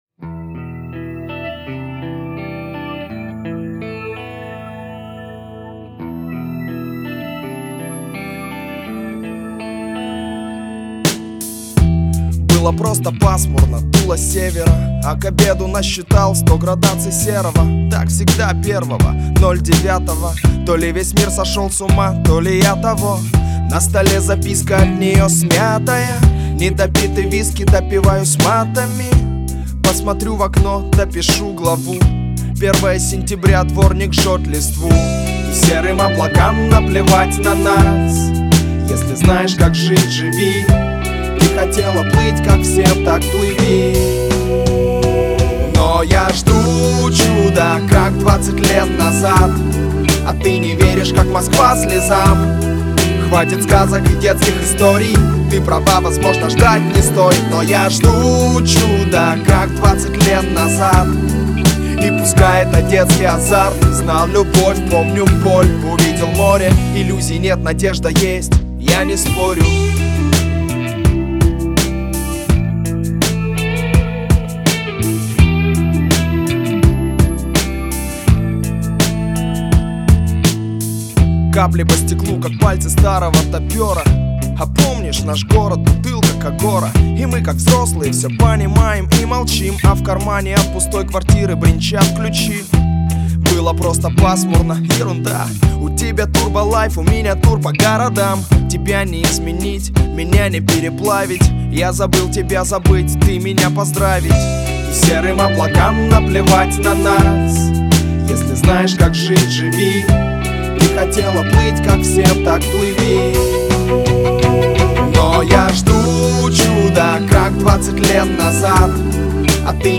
Это не песня, а рэп!